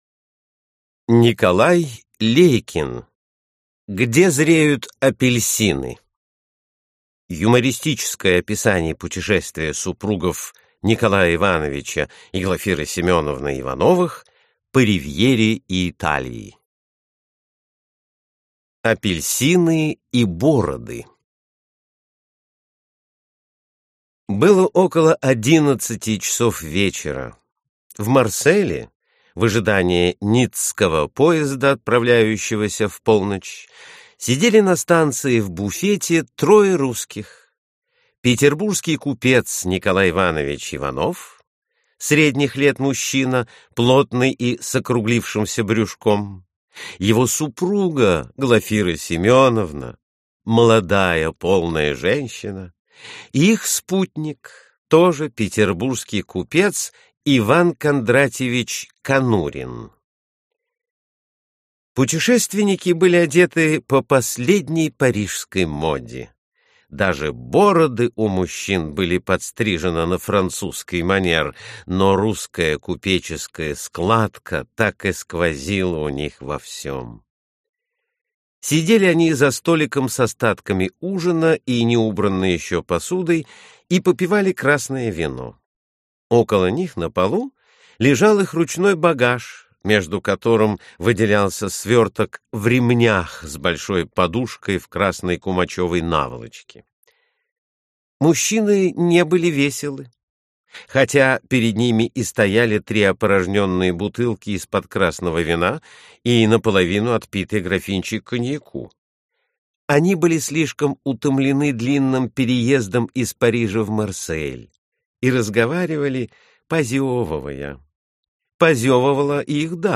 Аудиокнига Где зреют апельсины.